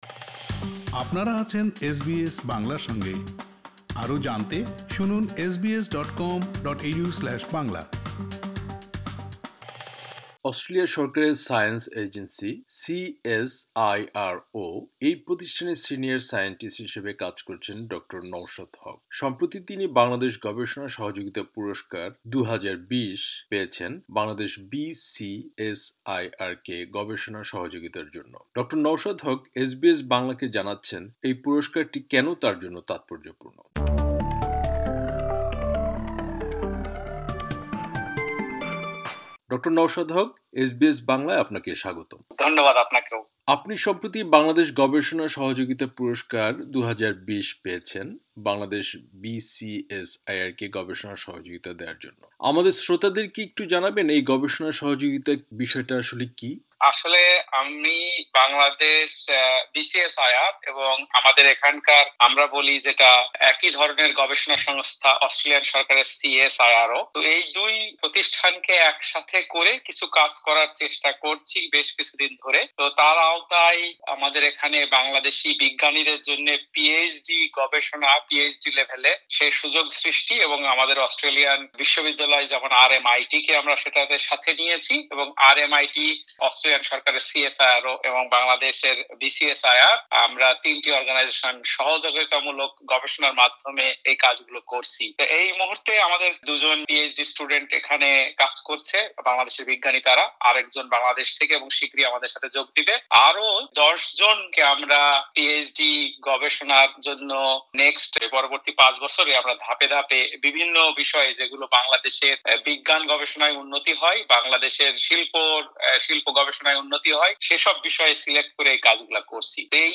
পুরো সাক্ষাতকারটি